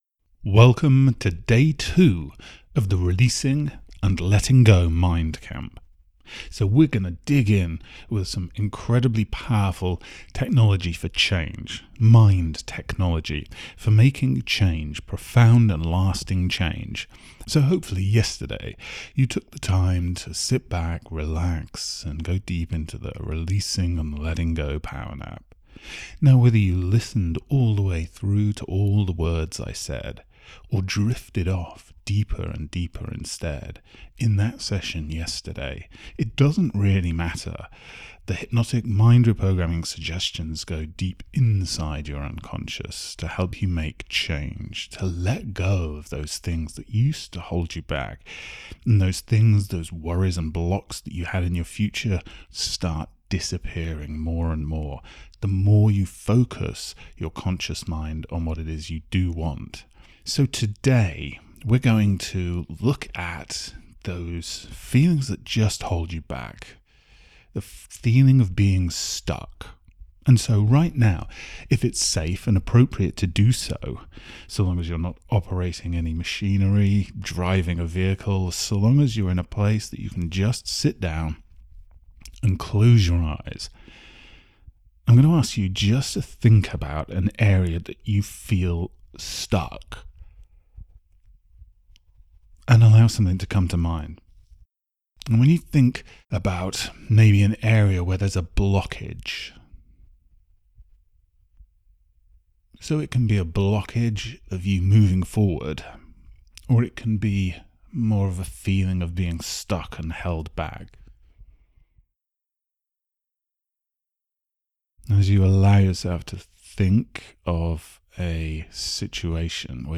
Its best to slip on a pair of headphones... get yourself comfortable, press play and relax with this 11 minute Hypnotic PowerNap for Releasing & Letting Go